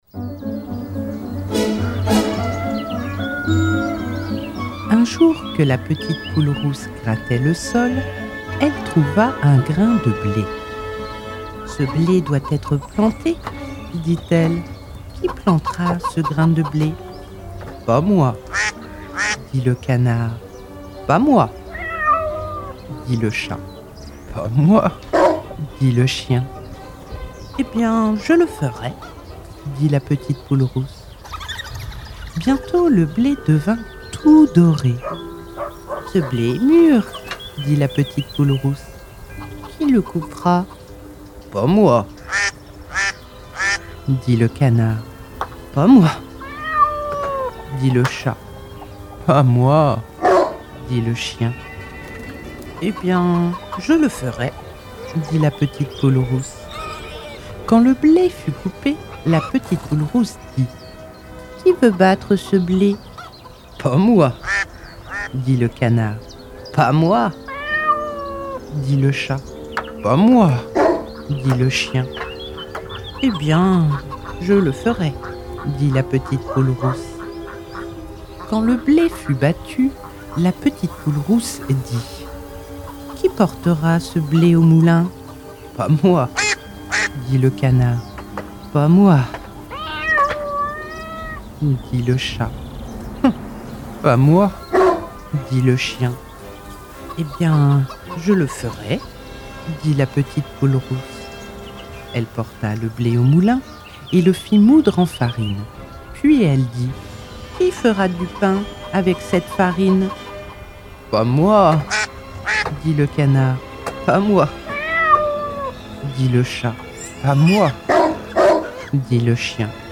Conte traditionnel (2:54)